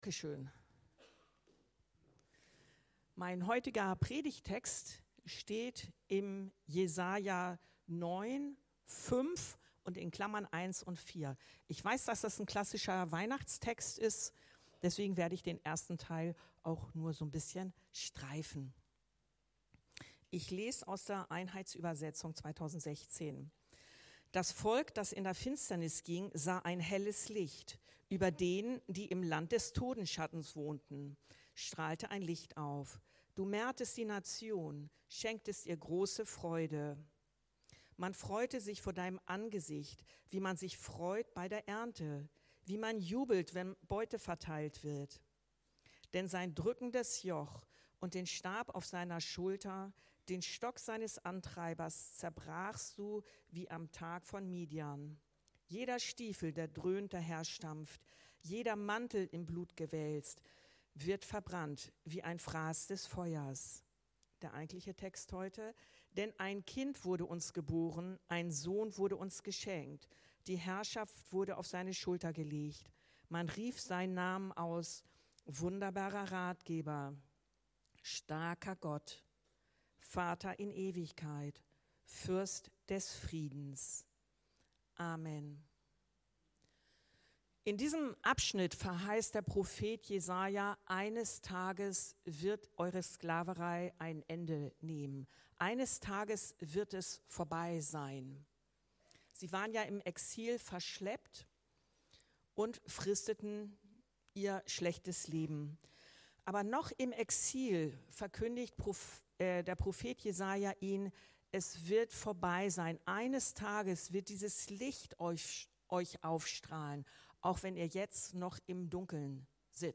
ER ist alles für uns ~ Anskar-Kirche Hamburg- Predigten Podcast